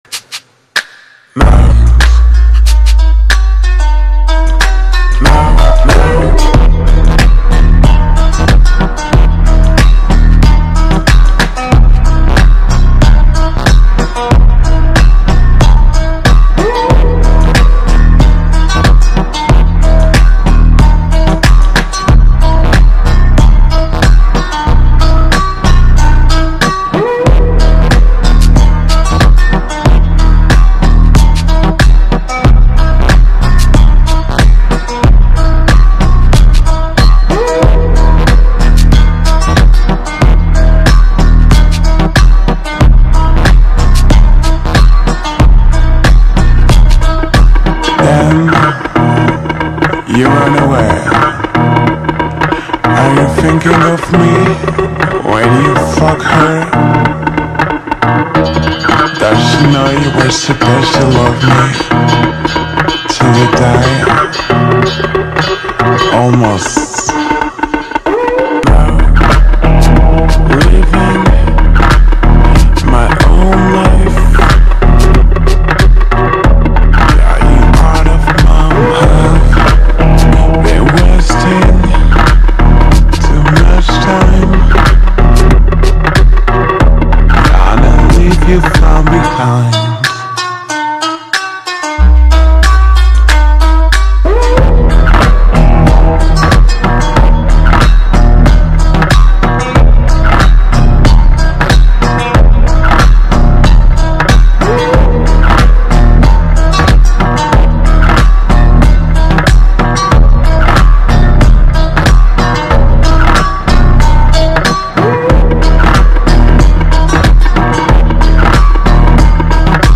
Ремикс отличается выразительным вокалом